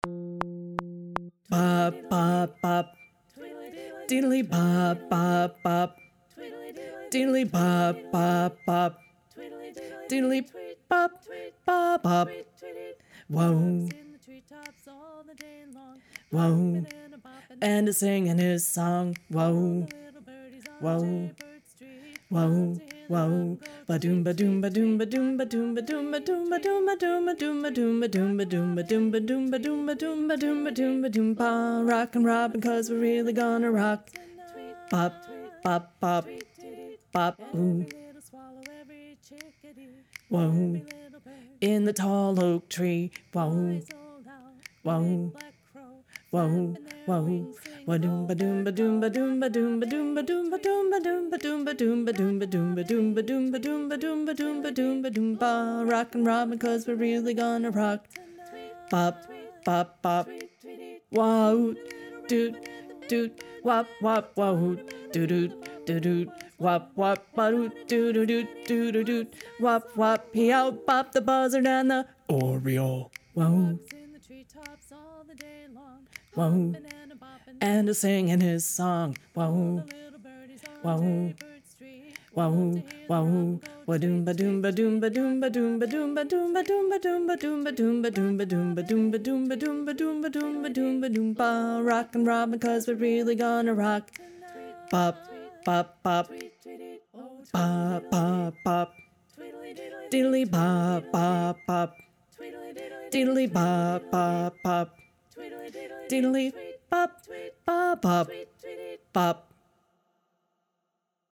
Alto 2 (Bass)